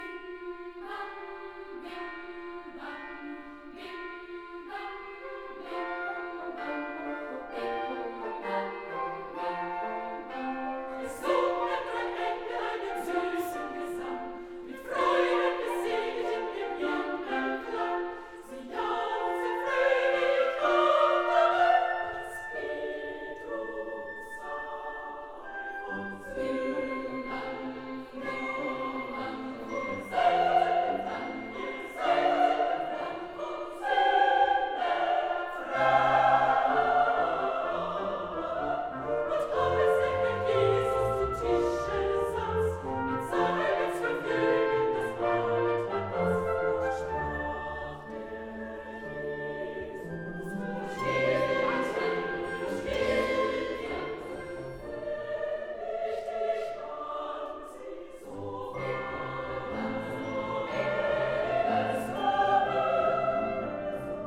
女低音